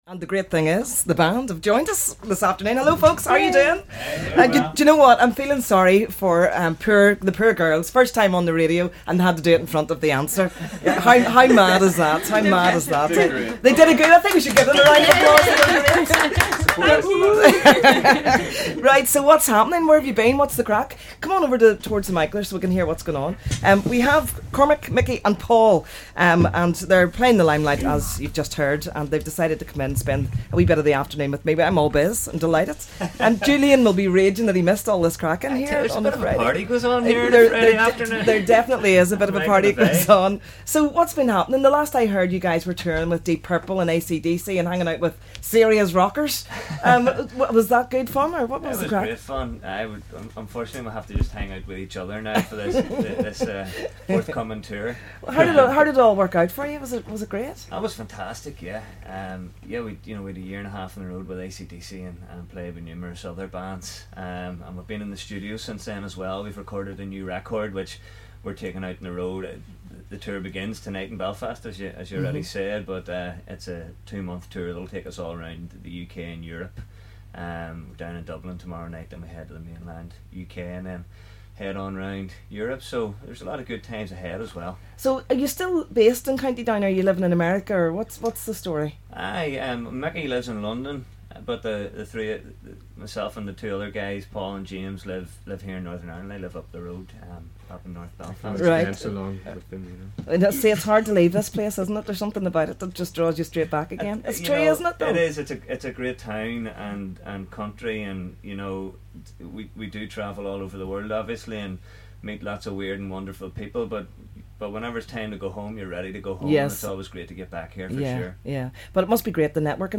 Interview with The Answer!
They even gave a fantastic acoustic performance ahead of their gig at The Limelight tonight!